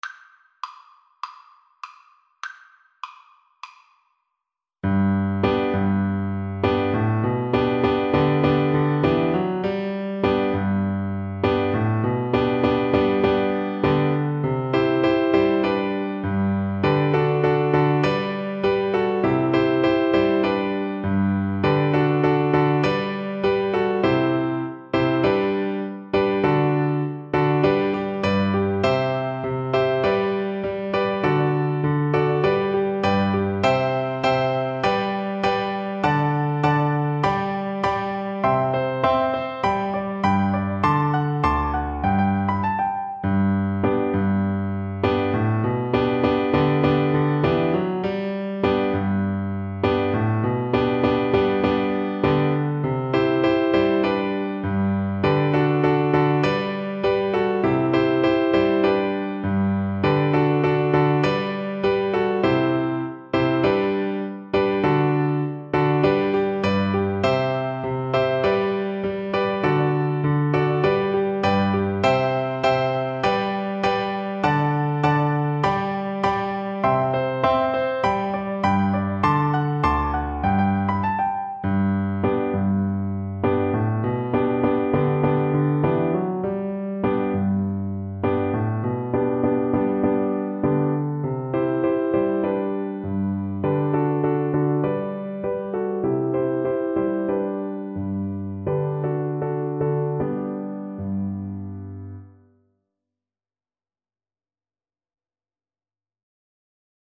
Flute version
G major (Sounding Pitch) (View more G major Music for Flute )
Joyfully =c.100
4/4 (View more 4/4 Music)
Flute  (View more Easy Flute Music)
Traditional (View more Traditional Flute Music)